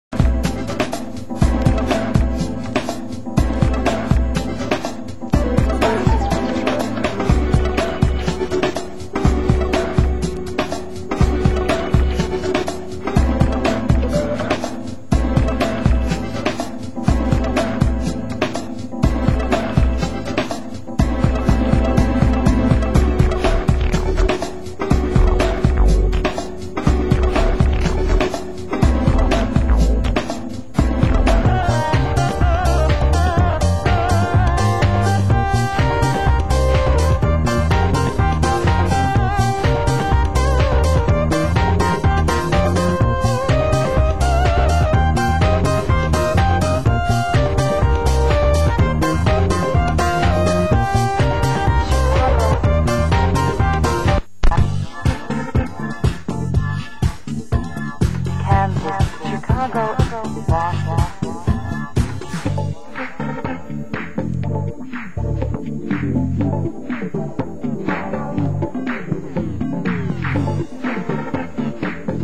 Format: Vinyl 12 Inch
Genre: Deep House